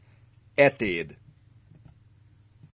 Atid (Hungarian: Etéd, pronounced [ˈɛteːd]